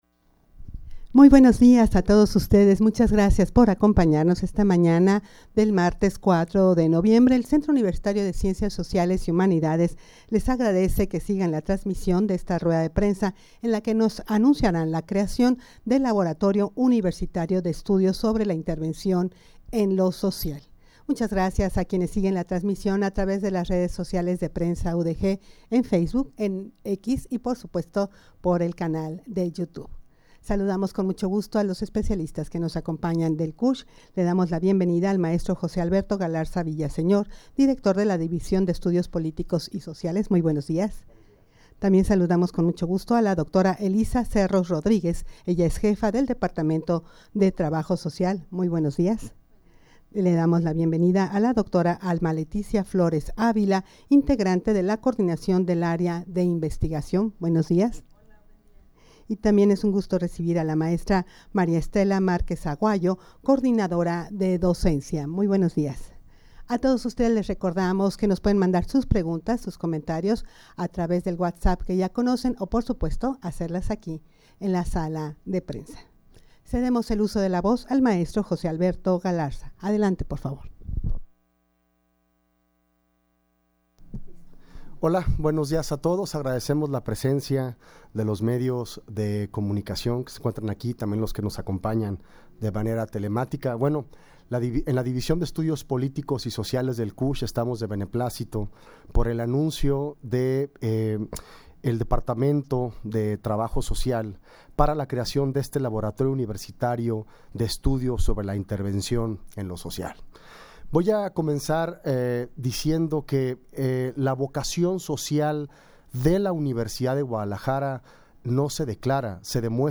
Audio de la Rueda de Prensa
rueda-de-prensa-creacion-del-laboratorio-universitario-de-estudios-sobre-la-intervencion-en-lo-social.mp3